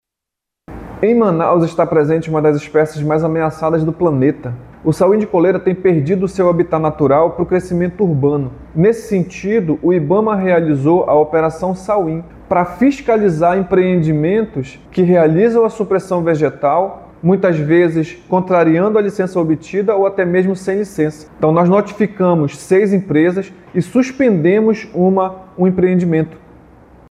De acordo com o superintendente do IBAMA no Amazonas, Joel Araújo, a proteção da espécie exige ações firmes e coordenadas do poder público.